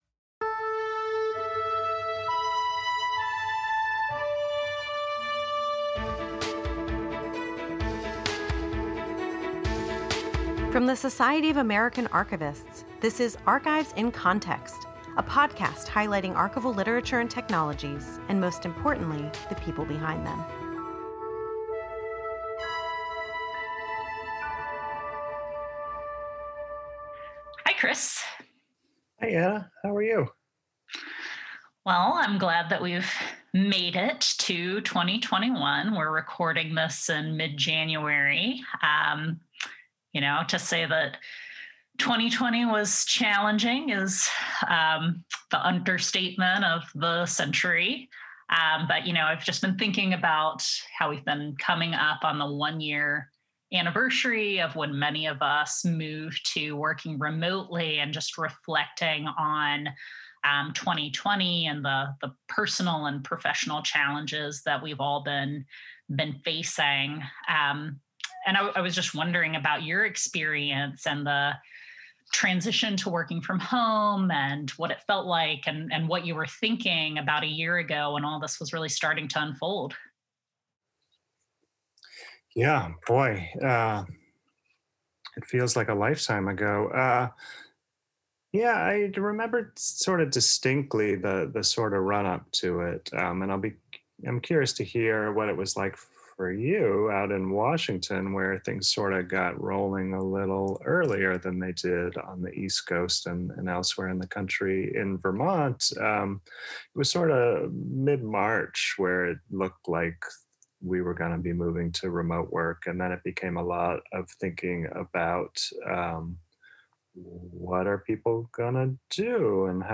In this special episode focused on archivists and archival work during COVID-19, we hear from you! Archivists called and wrote to us with their advice on navigating this challenging time and shared the ways they are finding joy, what they are thankful for, and how they are practicing self care. From embracing escapism to hiking or taking a nap, take a listen to colleagues’ strategies for finding silver linings and Zen moments.